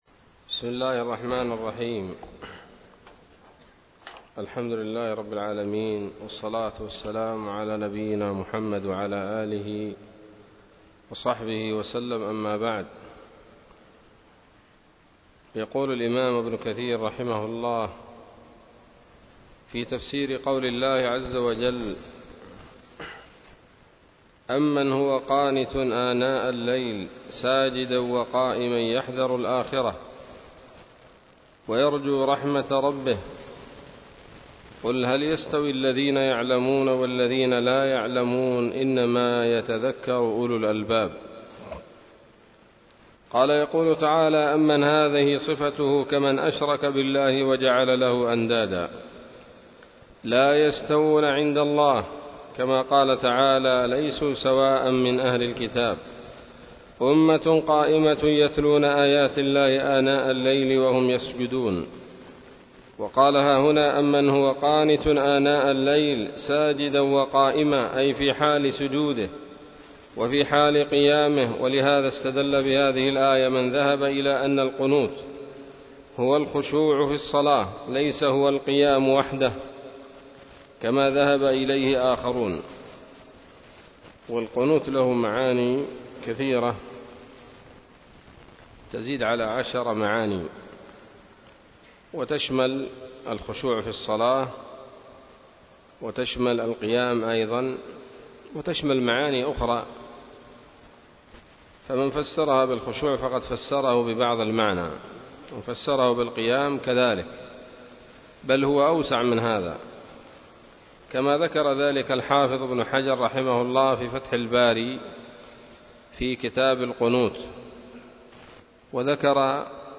الدرس الثالث من سورة الزمر من تفسير ابن كثير رحمه الله تعالى